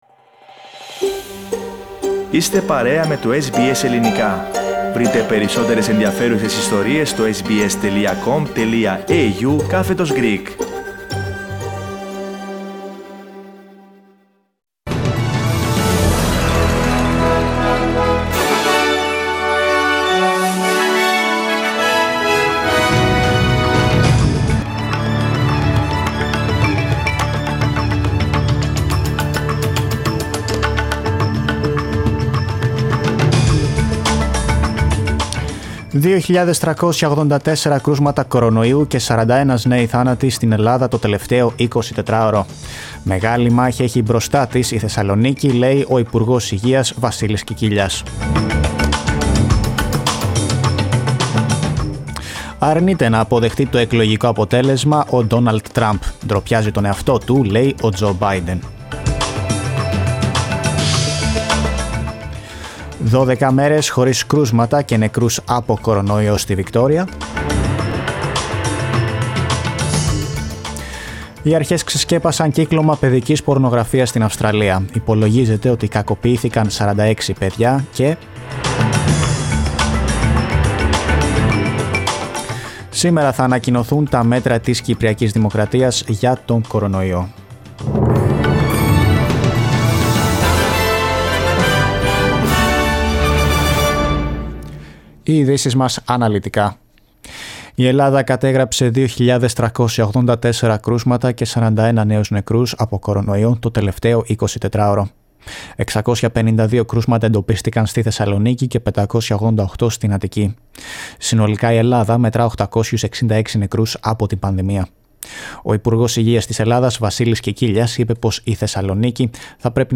News from Australia, Greece, Cyprus and the world in the news bulletin of Wednesday 11 of November.